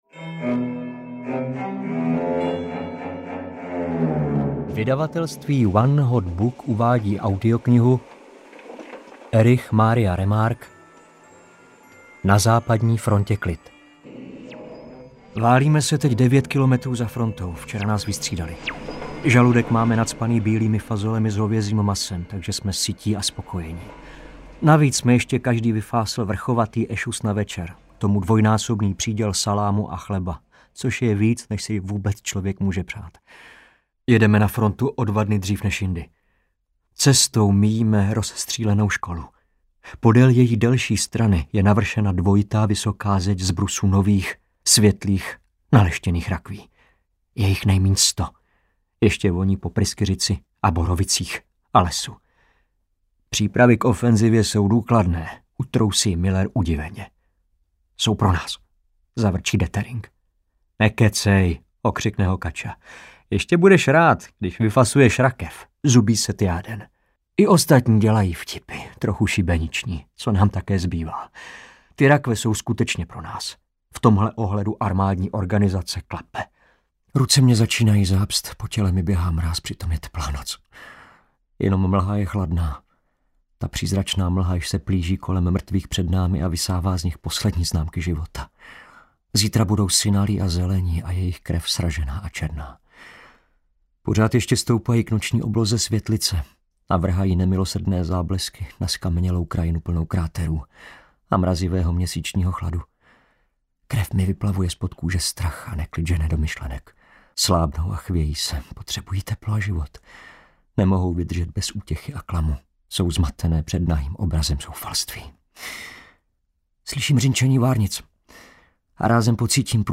Na západní frontě klid audiokniha
Ukázka z knihy
• InterpretJaroslav Plesl